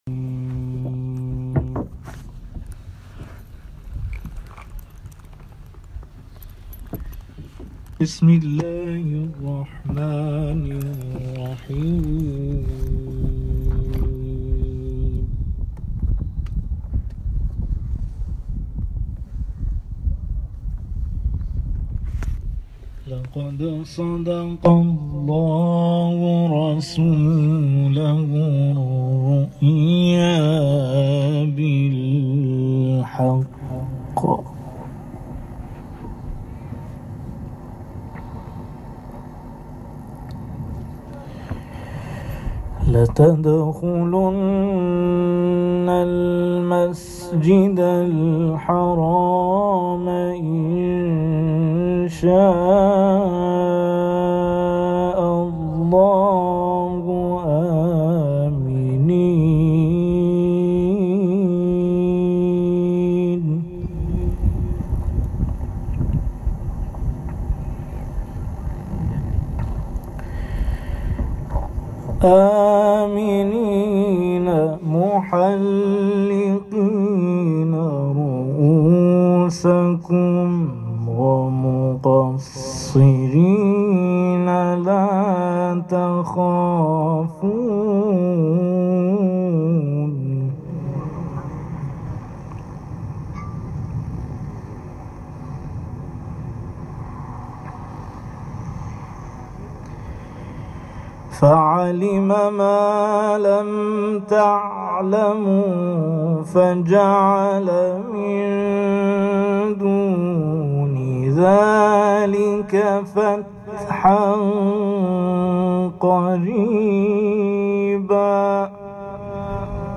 صوت/ قرائت قاری کاروان انقلاب در شناور شهید ناظری
گروه چندرسانه‌ای ــ کاروان قرآنی انقلاب در سیزدهمین روز از سفر خود، محافل انس با قرآن کریم در حسینیه امام خمینی(ره) ستاد نیروی دریایی سپاه هرمزگان، شناور شهید ناظرى در منطقه یکم دریایی صاحب الزمان (عج) و مسجد جامع جزیره ابوموسی برگزار کردند و در آن به تلاوت آیات کلام الله مجید پرداختند.